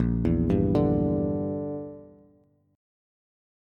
Bb6b5 Chord
Listen to Bb6b5 strummed